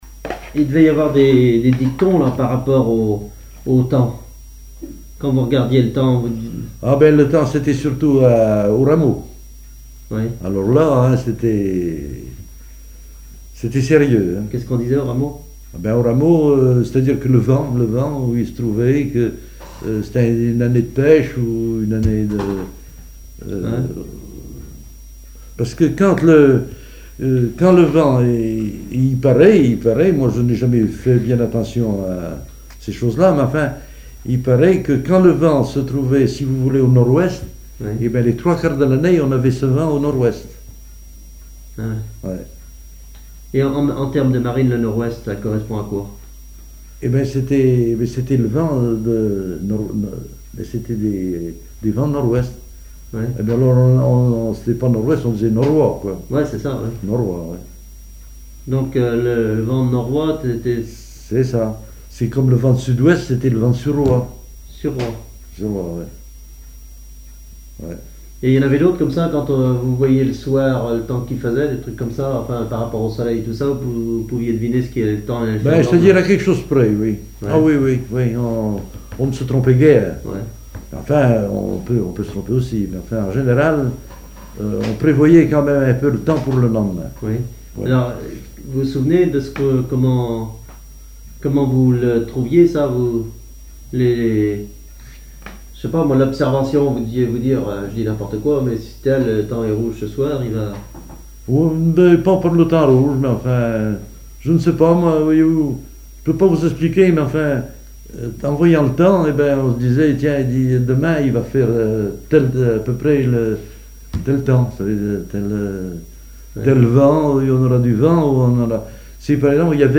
témoignages sur les activités maritimes locales
Témoignage